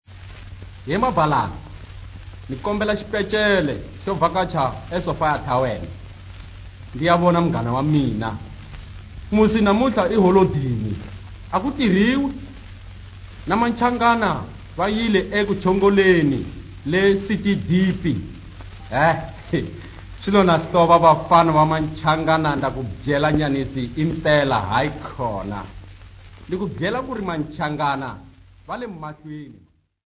Popular music--Africa
Field recordings
sound recording-musical